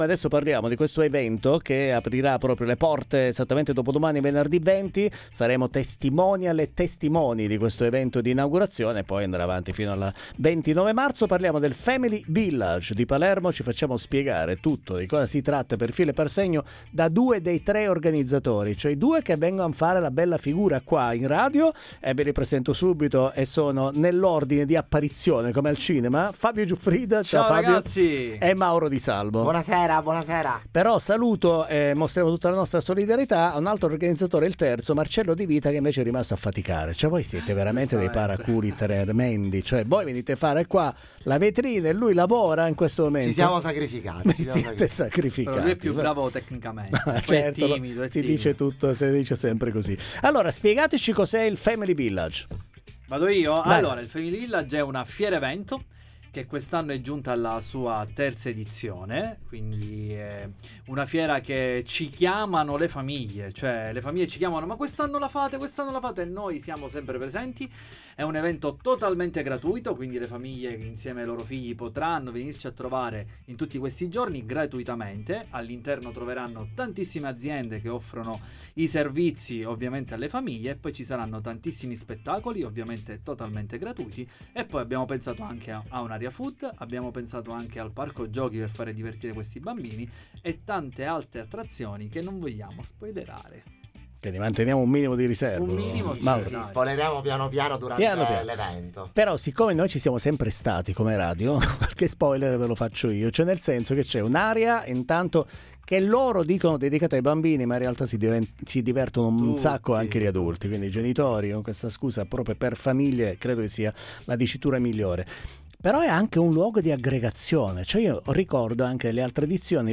Family village Palermo, la 1^ fiera dedicata ai bambini e alle famiglie dal 20 al 29 Marzo 2026 al Palagiotto di Palermo in Piazza Chinnici, ne parliamo con gli organizzatori in diretta
Interviste